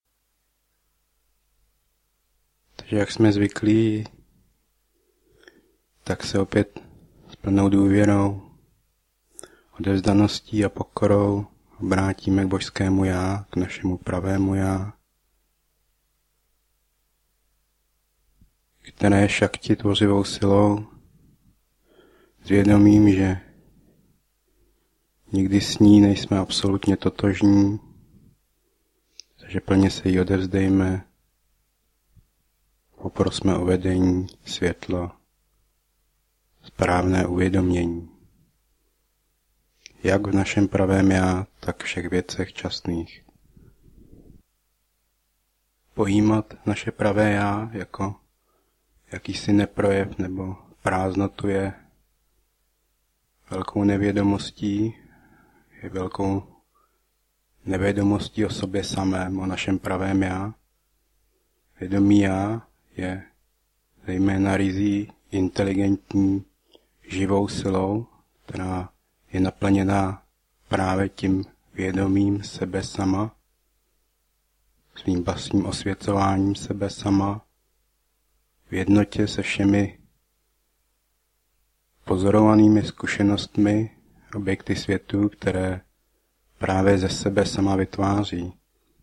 Šaktický aspekt átmavičáry. Zvukový záznam z pravidelné meditace přátel v klubovně Duchovní srdce z 3. 2. 2008, která byla přenášena do Bratislavy. Meditace trvala 3 hodiny, záznam je z časových důvodů zkrácen.